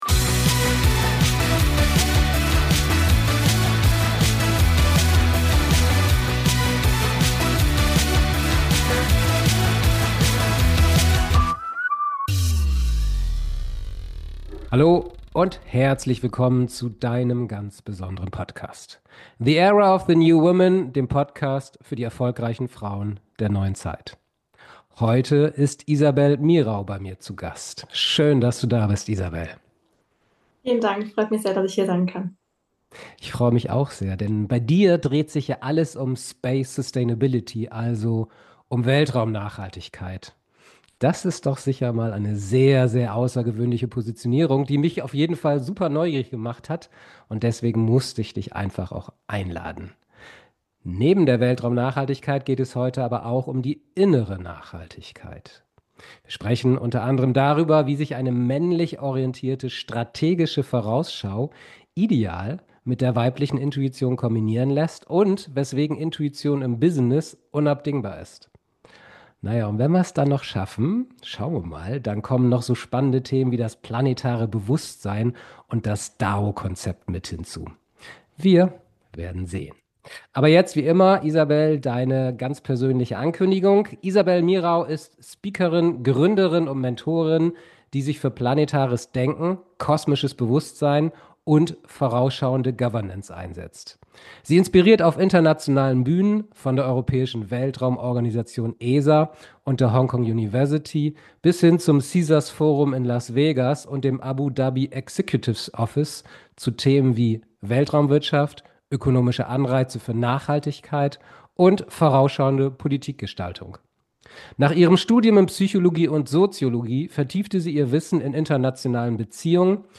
#052 Wir werden am Weltraummüll ersticken. Das Interview